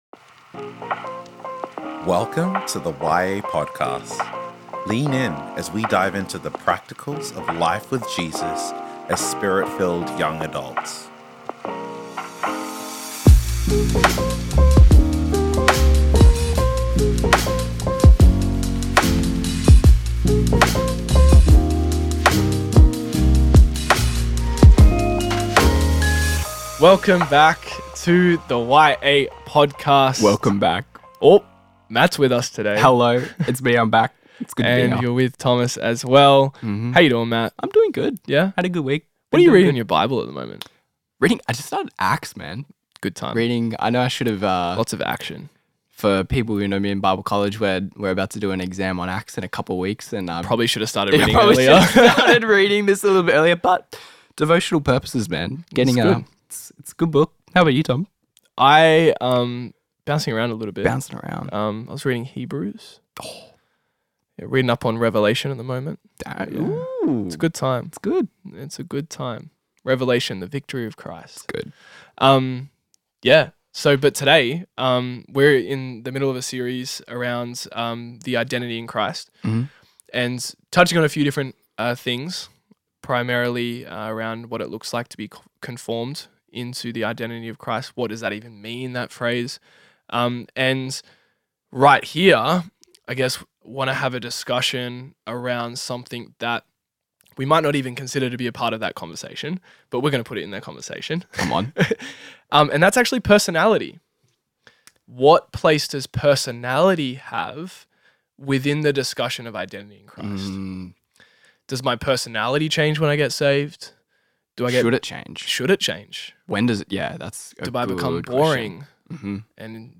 Conversations: Identity and Personality